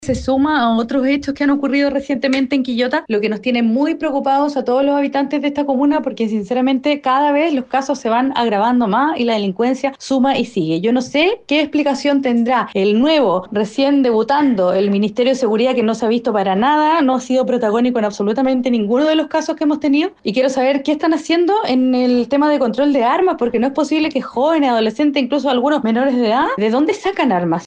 Por otra parte, la concejala de Renovación Nacional, Regina Brito, mencionó que esta situación se suma a otros hechos que han ocurrido en Quillota durante el último tiempo y cuestionó la labor del nuevo Ministerio de Seguridad.